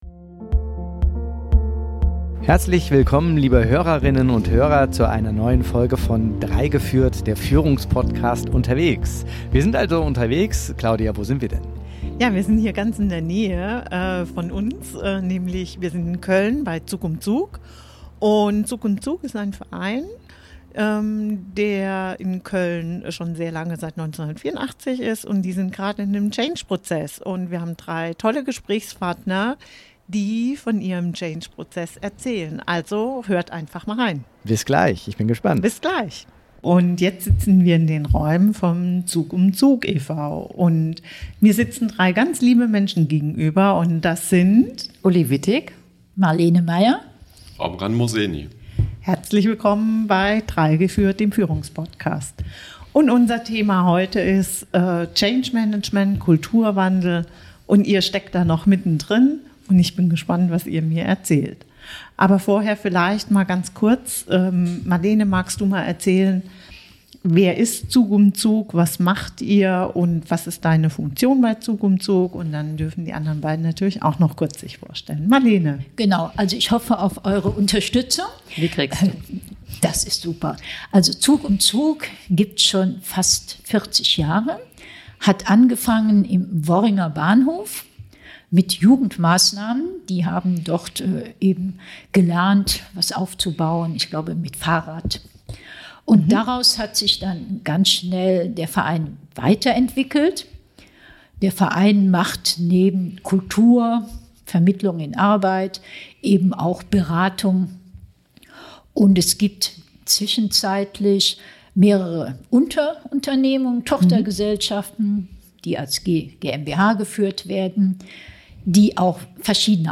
Ein ehrliches Gespräch über Mut, Lernen und Entwicklung – mitten aus der Praxis.